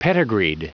Prononciation du mot pedigreed en anglais (fichier audio)
pedigreed.wav